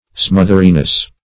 Smotheriness \Smoth"er*i*ness\, n.